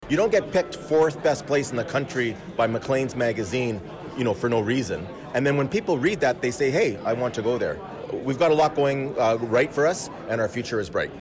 That was the theme of Wednesday’s State of the City address by the mayor of Belleville Mitch Panciuk.
Panciuk was speaking to members of the Belleville Chamber of Commerce at The Grand in the city’s west end.